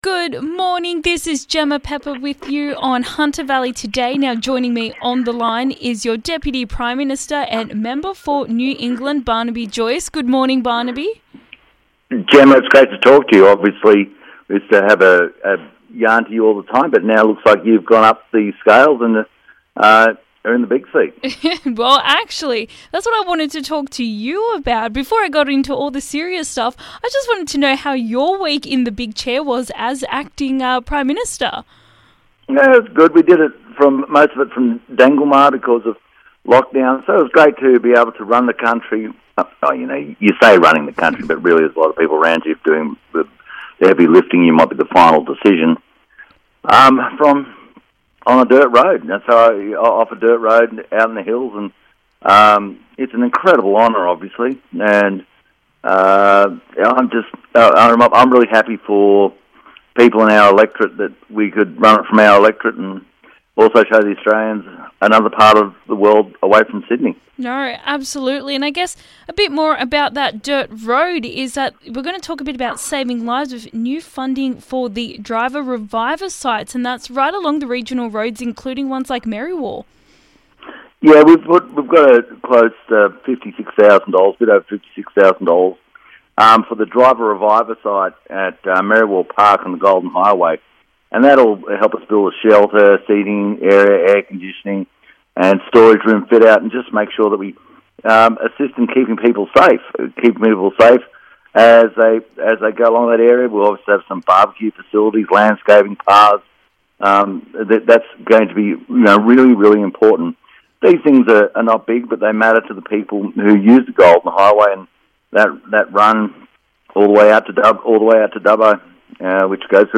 Interview with Deputy Prime Minister Barnaby Joyce discussing driver revive sites, the federal Hunter seat and vaccinations